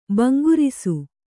♪ bangurisu